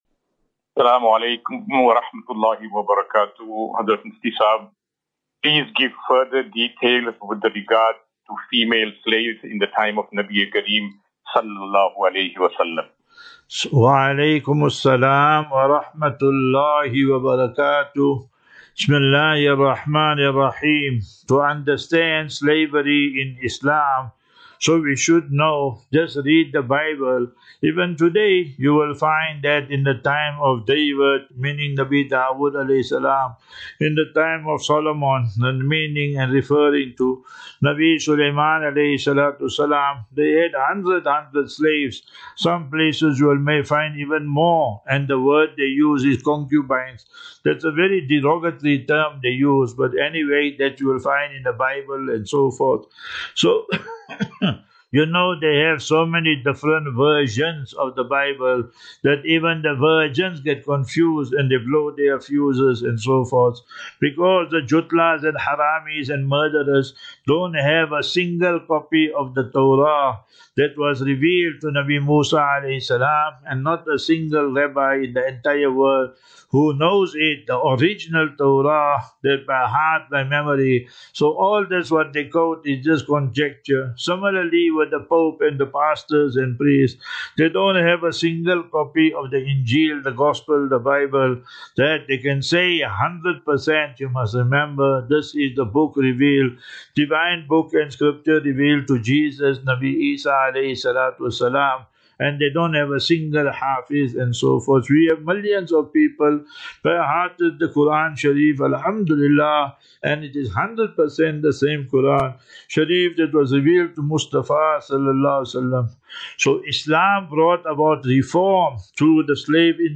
View Promo Continue Install As Safinatu Ilal Jannah Naseeha and Q and A 18 Mar 18 March 2025.